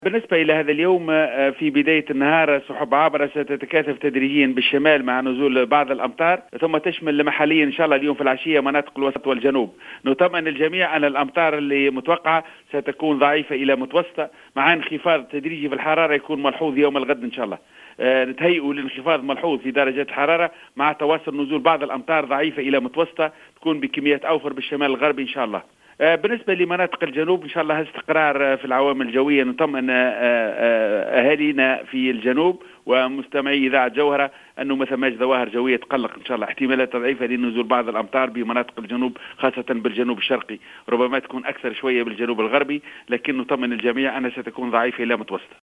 في اتصال هاتفي للجوهرة أف أم